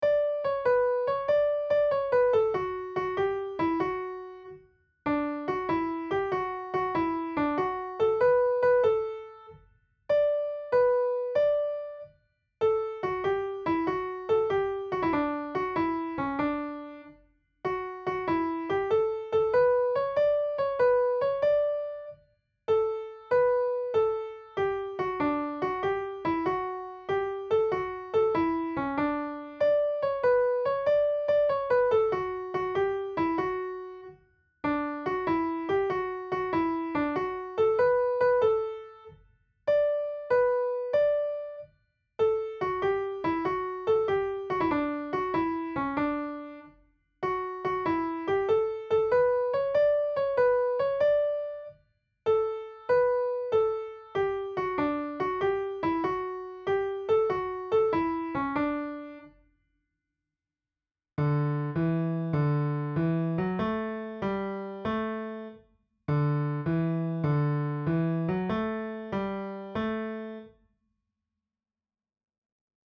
Summer-is-A-Coming-In-Round.mp3